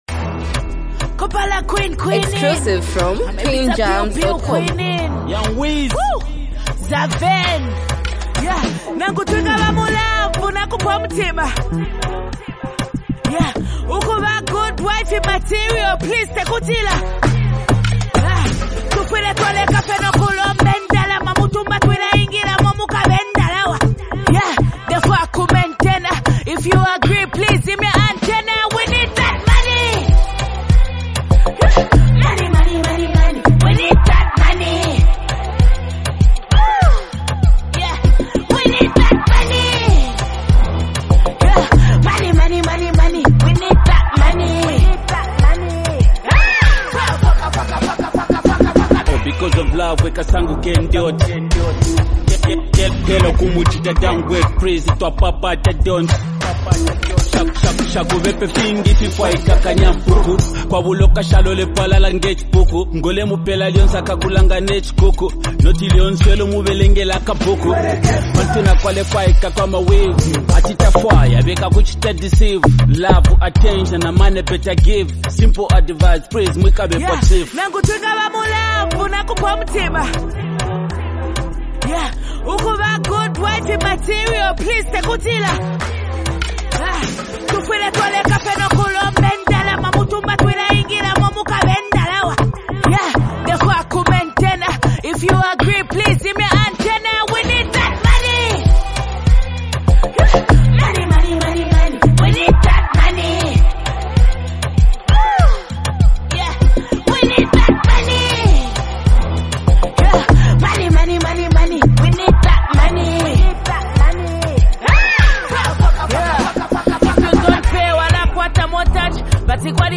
Through confident lyrics and a catchy beat